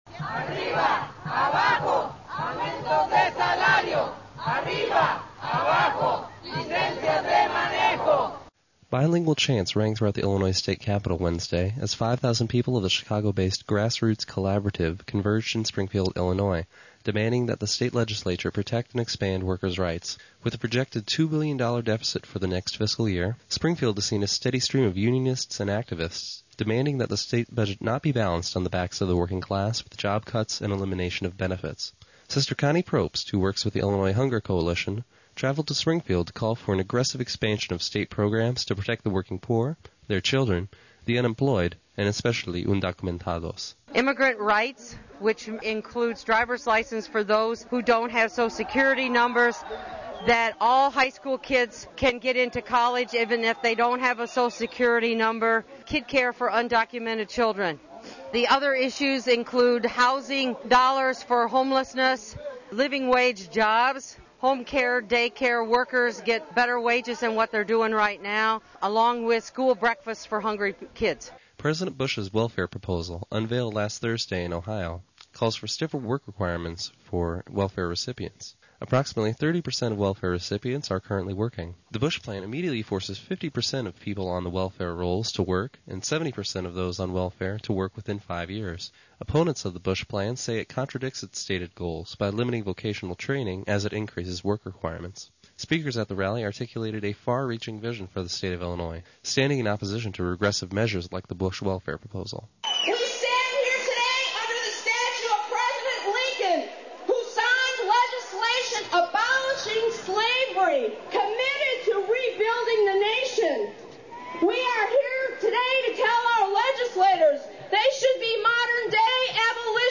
IMC Radio News for 13 May 2002